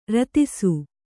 ♪ ratisu